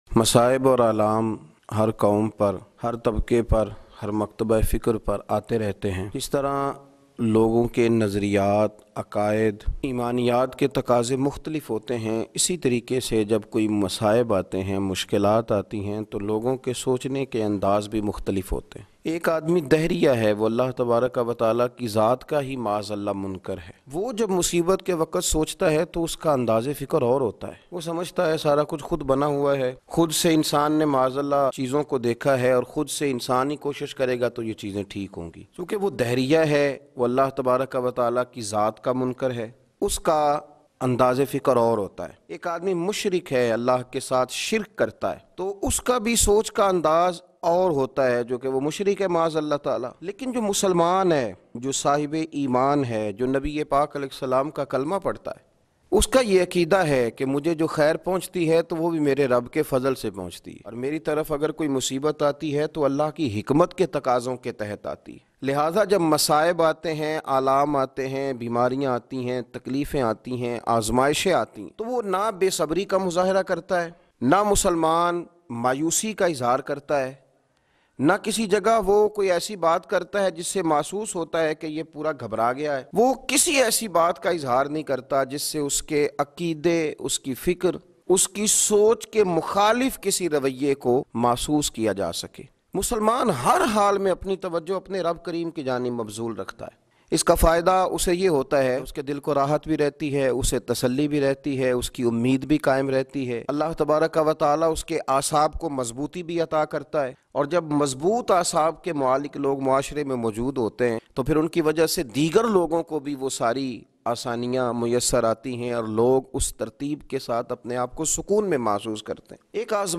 Masaib Kay Dor Mein Musalman Ki Soch Bayan MP3